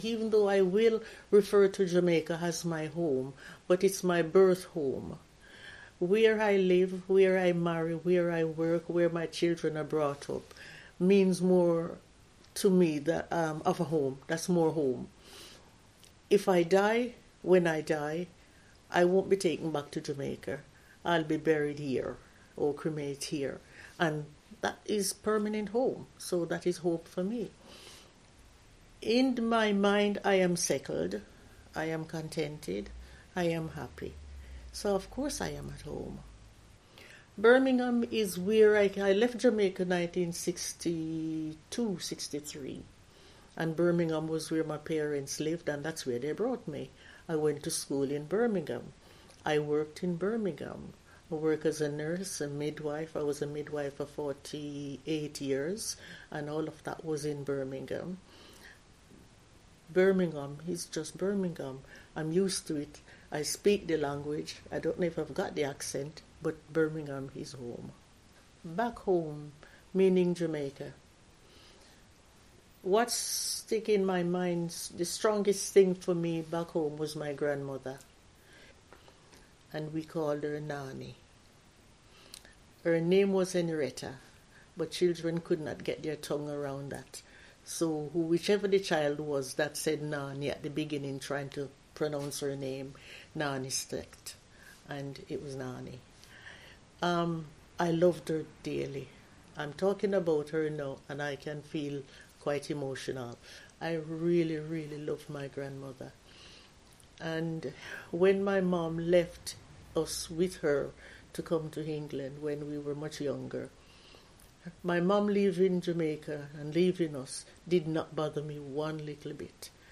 We worked with photographer Vanley Burke and older African-Caribbean women to create Home.